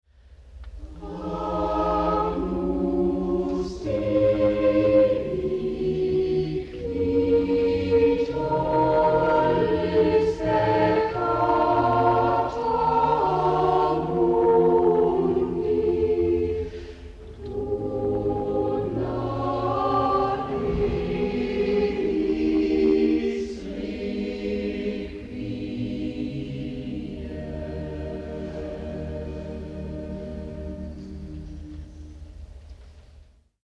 die sätze für ein requiem für chor und orgel
in der laboer anker-gottes-kirche